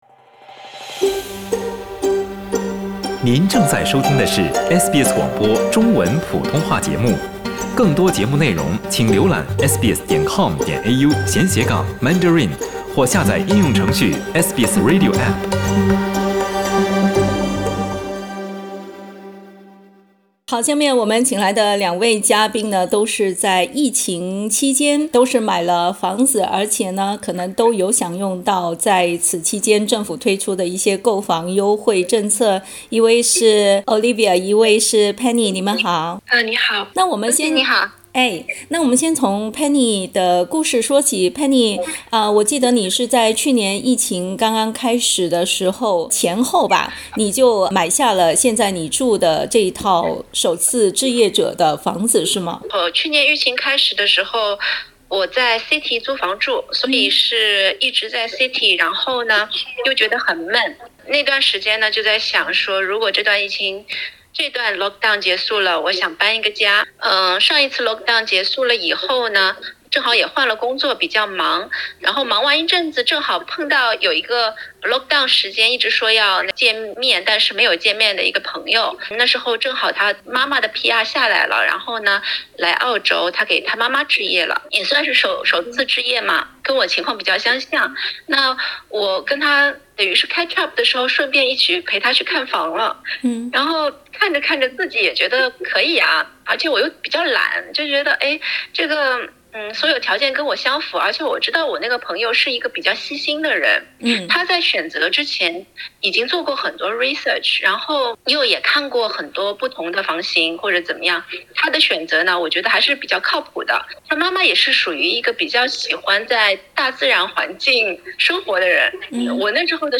（请听采访，本节目为嘉宾观点，不代表本台立场） 澳大利亚人必须与他人保持至少1.5米的社交距离，请查看您所在州或领地的最新社交限制措施。